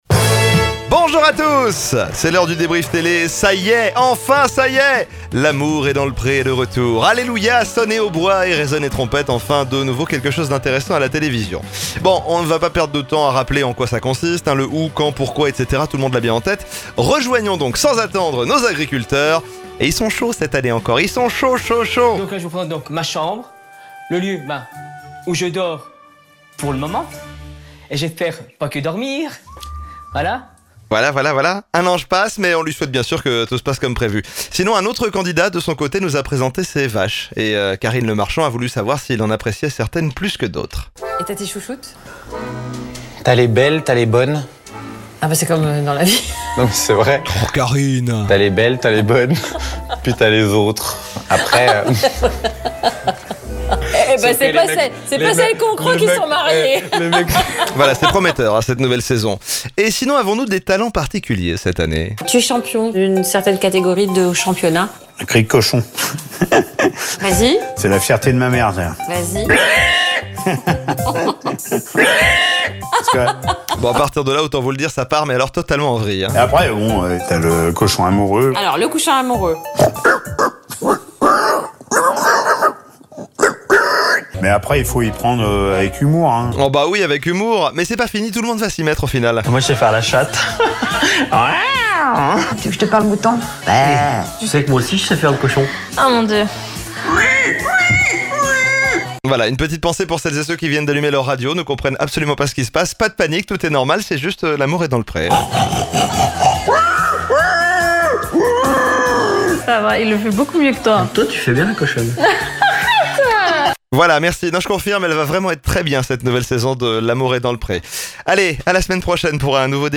MAXI L’AIR propose une chronique riche en contenus pour revenir sur les meilleurs moments de la télévision : zappings, moments amusants, dramas, extraits … le tout dans une écriture amusante.